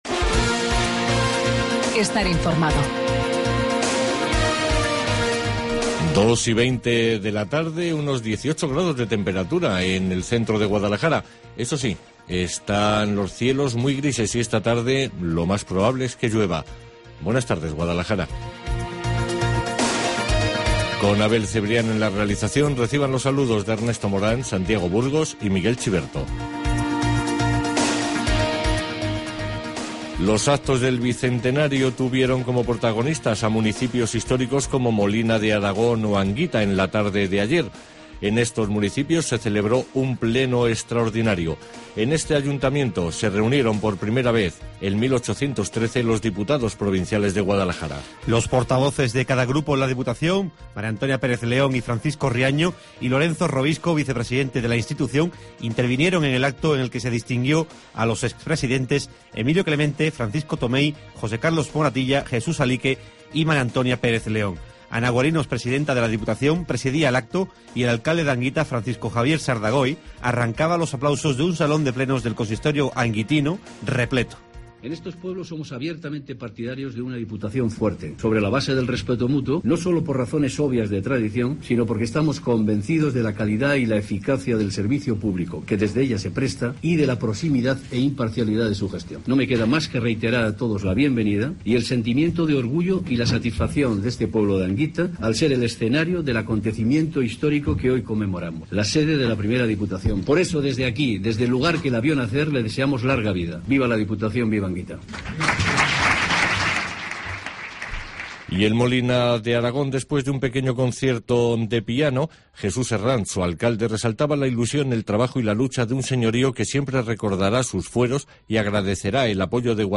Informativo Guadalajara 26 DE ABRIL